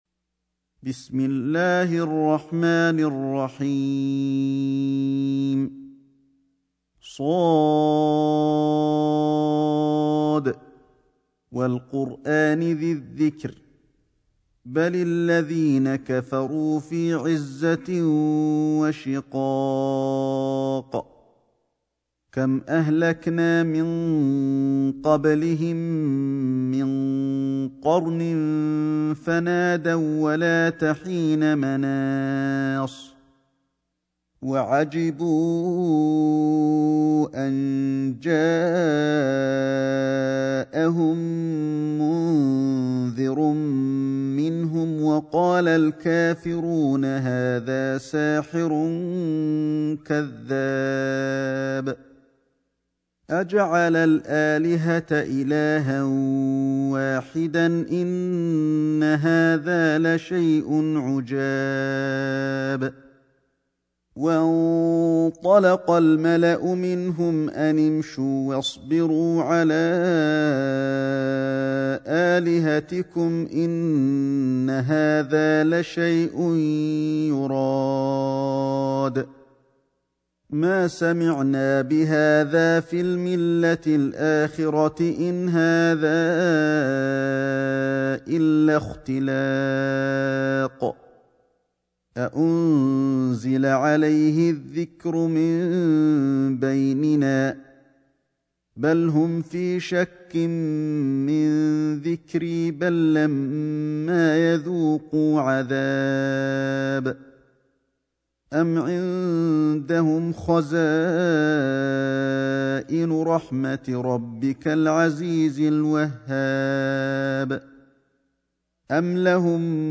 سورة ص > مصحف الشيخ علي الحذيفي ( رواية شعبة عن عاصم ) > المصحف - تلاوات الحرمين